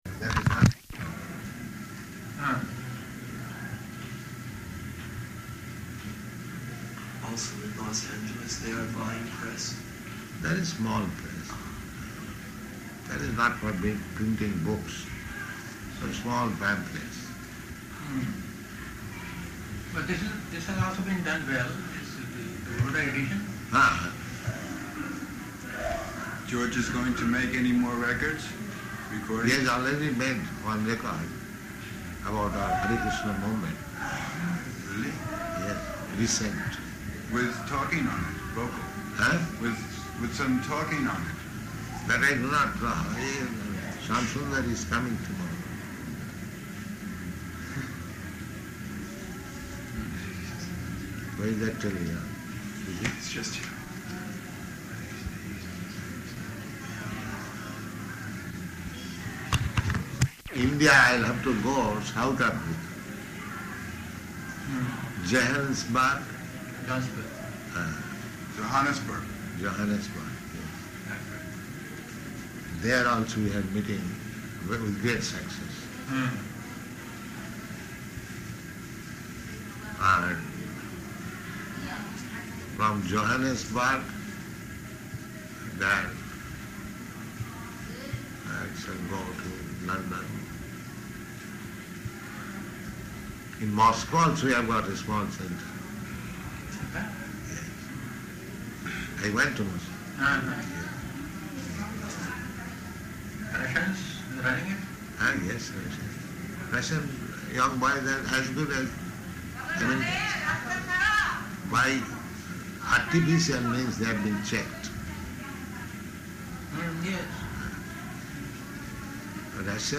Room Conversation
Room Conversation --:-- --:-- Type: Conversation Dated: October 25th 1972 Location: Vṛndāvana Audio file: 721025R3.VRN.mp3 Devotee: Also in Los Angeles they are buying press?